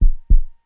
heart_beats
fast_3.wav